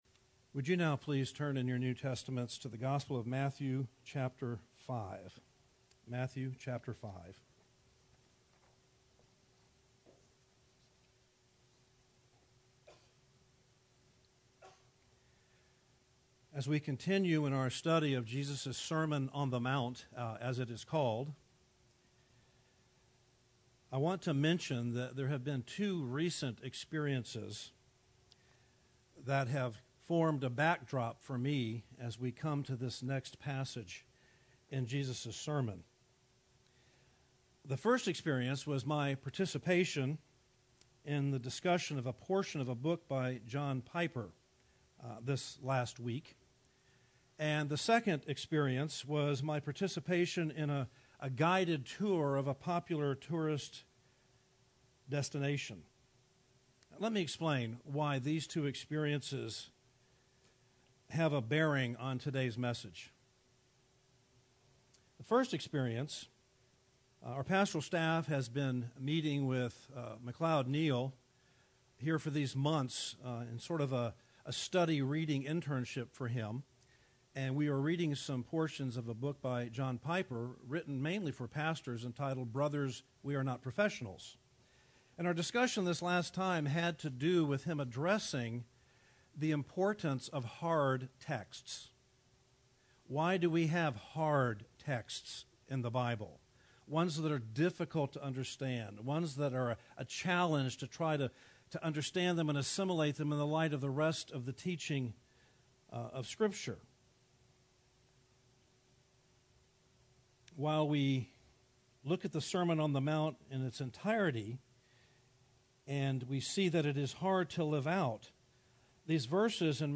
Series: Jesus' Sermon on the Mount